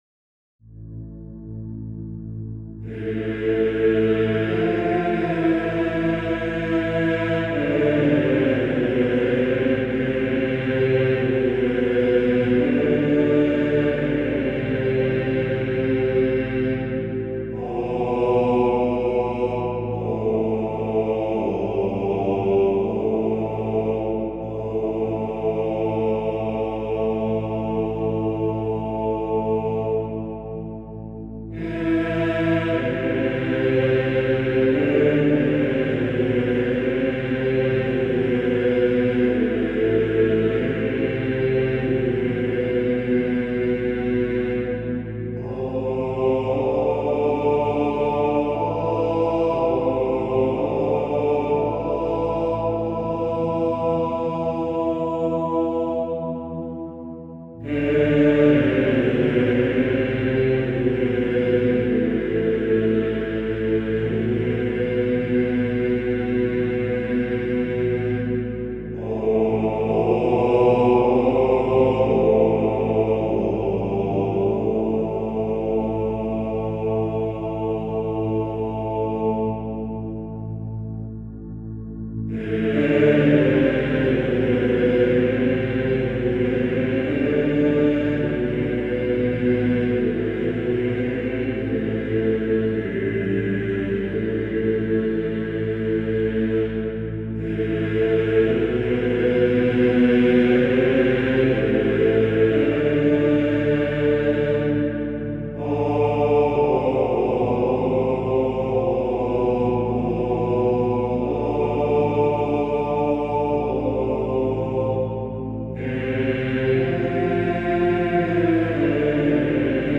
Genre: Talk Show